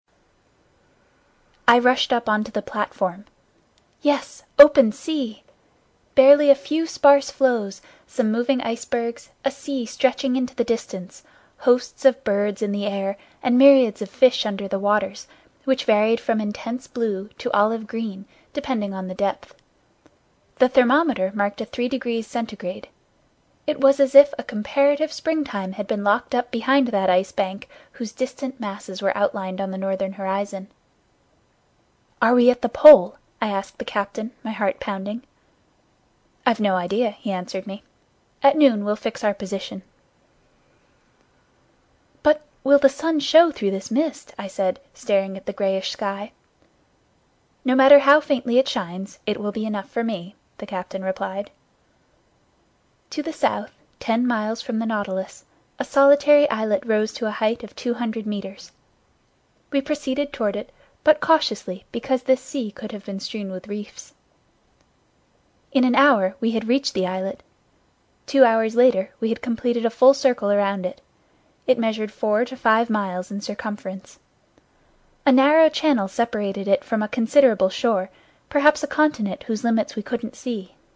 英语听书《海底两万里》第442期 第27章 南极(1) 听力文件下载—在线英语听力室
在线英语听力室英语听书《海底两万里》第442期 第27章 南极(1)的听力文件下载,《海底两万里》中英双语有声读物附MP3下载